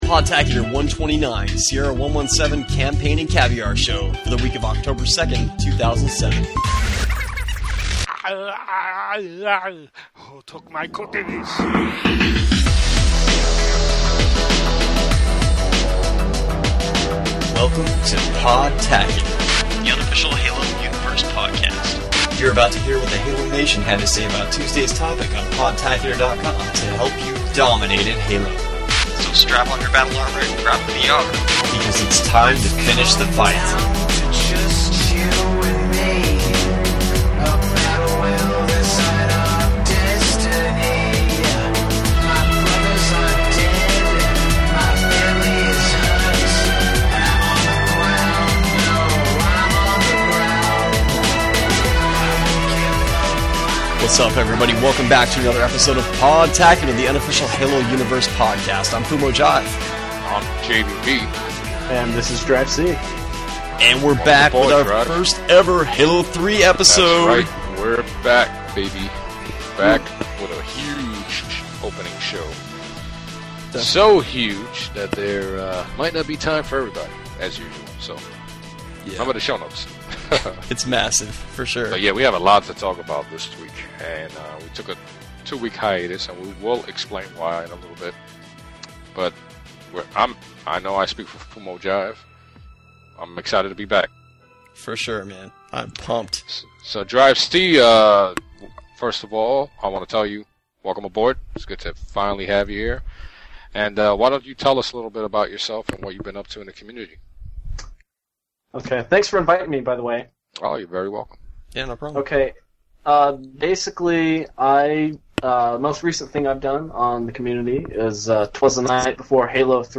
Shiny new audio work, interviews with HBO and Bungie at the Halo 3 launch event, and talk about strategies, graphics, sound, and the story for Sierra 117. All wrapped up with more outtakes at the end.